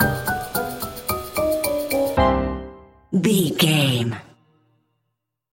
Aeolian/Minor
percussion
flute
orchestra
piano
goofy
comical
cheerful
Light hearted
quirky